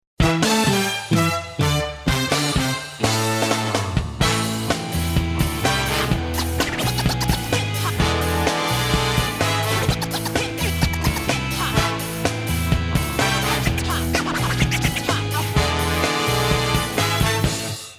• Качество: 256, Stereo
веселые
без слов
инструментальные
ска